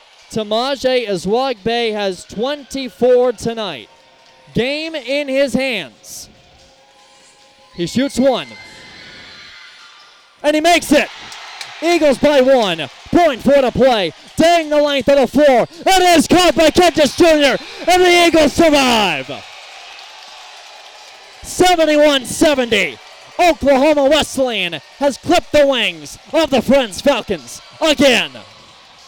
Here's how the ending sounded on KPGM.